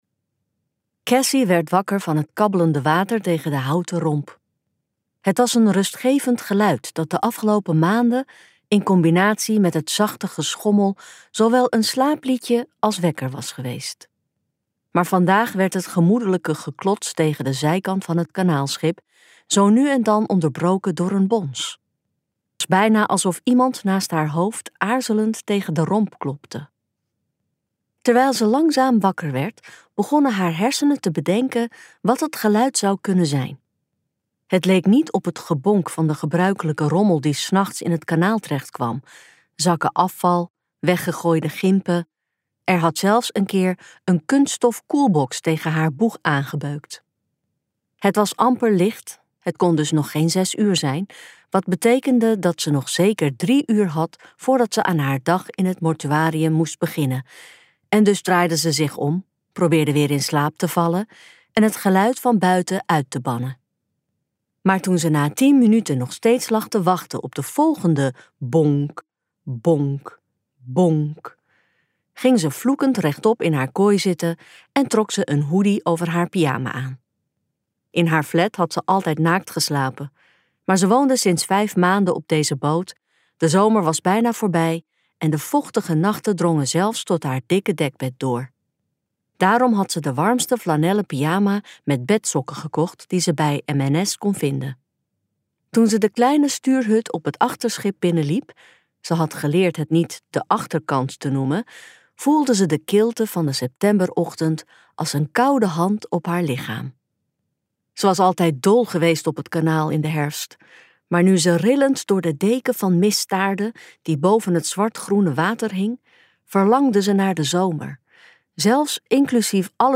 Ambo|Anthos uitgevers - Hoofdzaak luisterboek